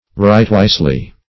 Meaning of rightwisely. rightwisely synonyms, pronunciation, spelling and more from Free Dictionary.
rightwisely - definition of rightwisely - synonyms, pronunciation, spelling from Free Dictionary Search Result for " rightwisely" : The Collaborative International Dictionary of English v.0.48: Rightwisely \Right"wise`ly\, adv.